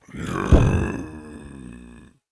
behemoth_die4.wav